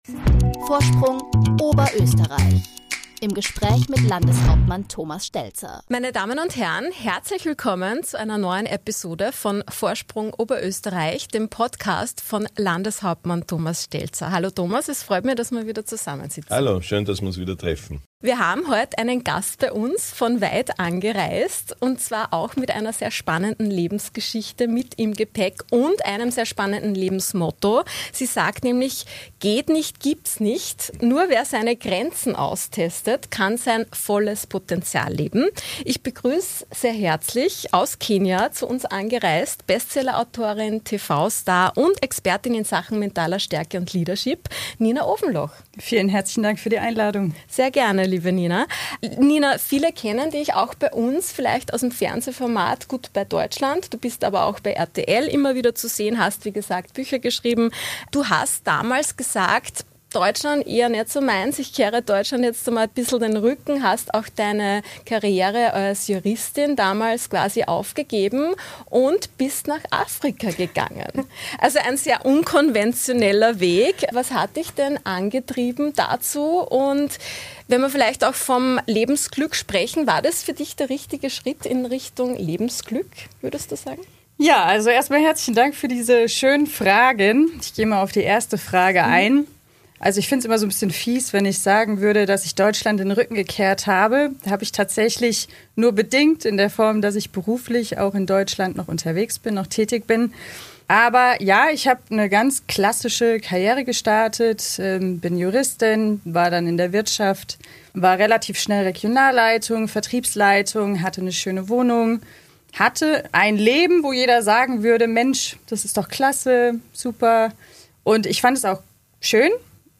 In einem sehr angenehmen Gespräch berichtet sie von diesen prägenden Erfahrungen.